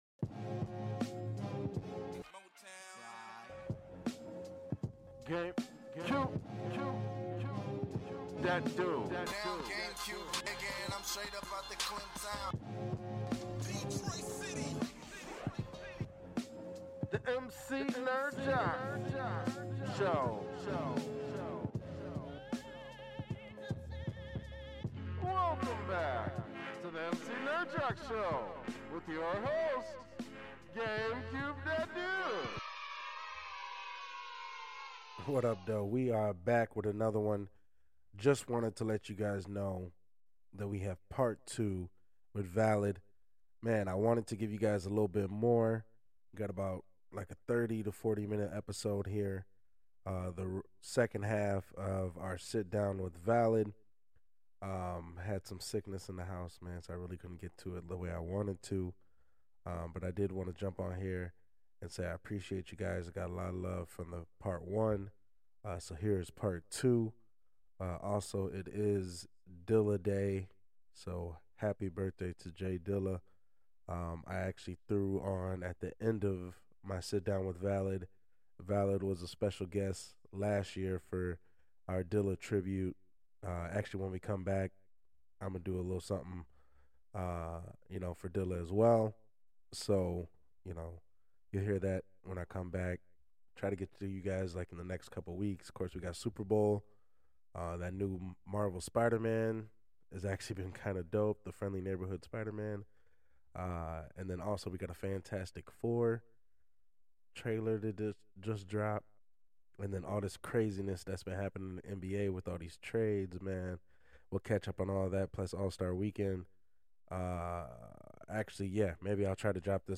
Part 2 of the sitdown